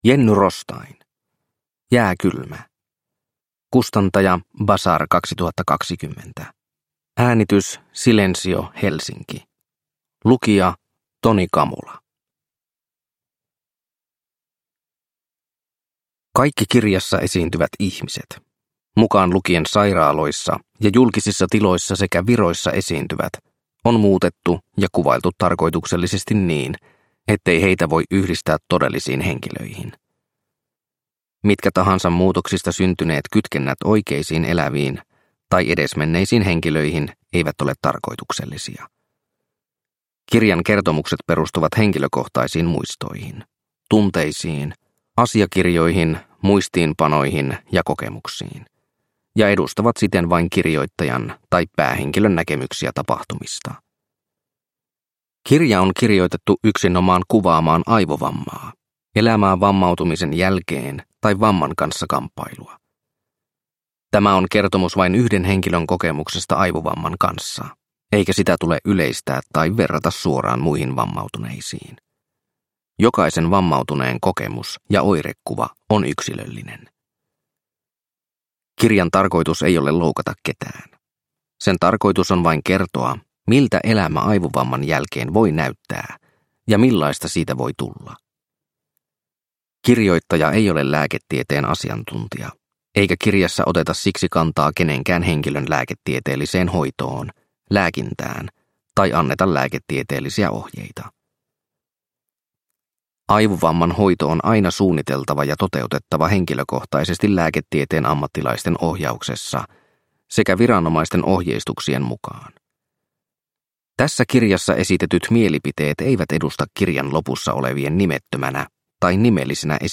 Jääkylmä – Ljudbok – Laddas ner